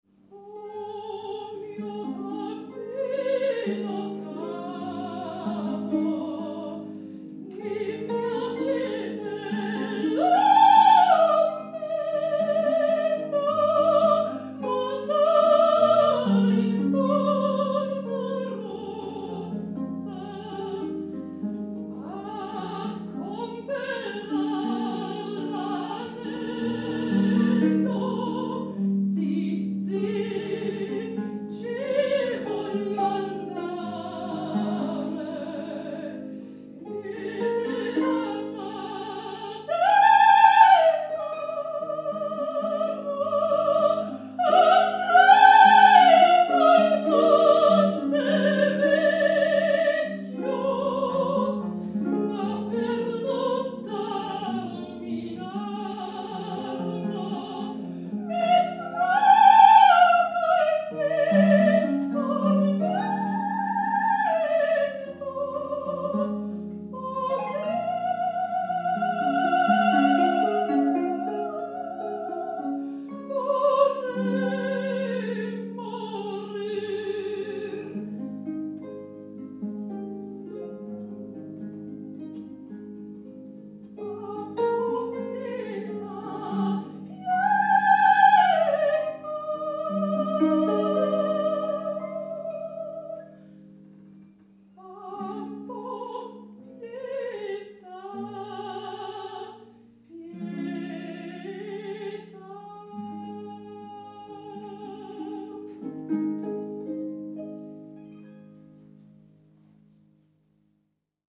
I konserten Om Bland Tusen Stjärnor tolkar och förmedlar operaensemblen Stelle Polari arior och sånger med ackompanjemang
av glittrande harpa och piano.
operaarior och solo för harpa.